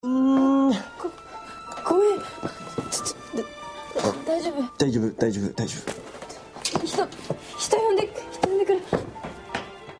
Kita akan mengangkat salah satu adegan dari dorama Nobuta o Produce episode 7 yang menggunakan -te kuru berkali-kali.